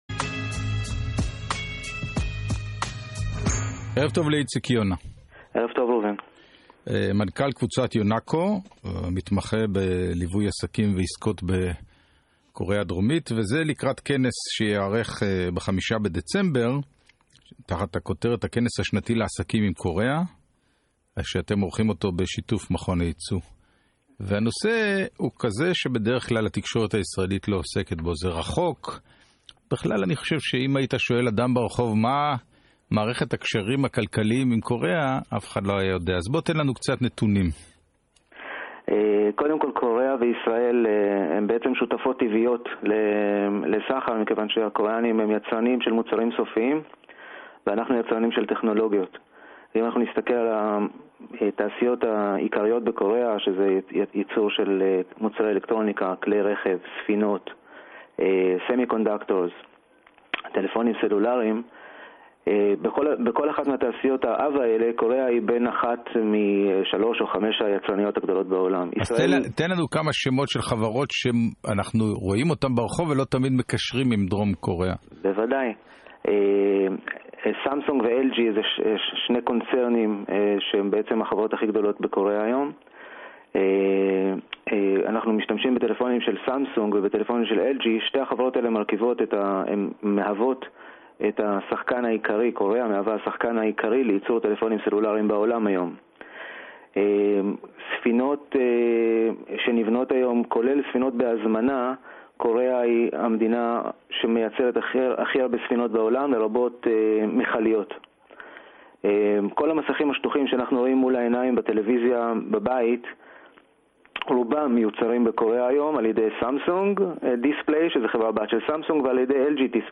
Interview from GLZ